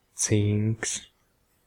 Ääntäminen
France: IPA: [lə zɛ̃ɡ]